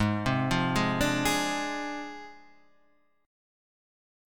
G# 6th Flat 5th